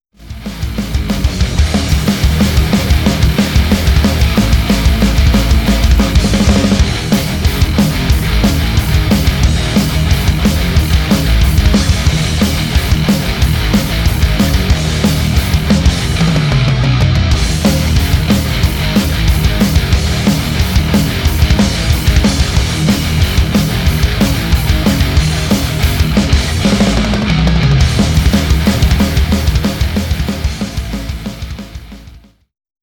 Rock/Metal
Drums with parallel compression